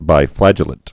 (bī-flăjə-lĭt, -lāt)